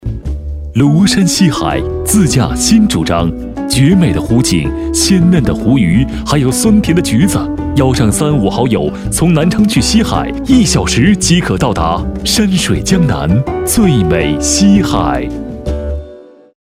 男声配音
温暖